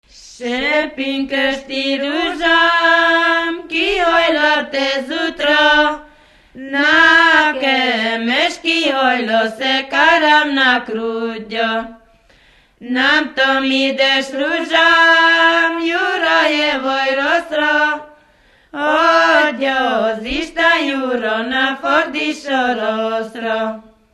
Moldva és Bukovina - Moldva - Külsőrekecsin
ének
Stílus: 7. Régies kisambitusú dallamok
Szótagszám: 6.6.6.6
Kadencia: 5 (4) 1 1